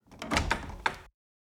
Door Open 2.ogg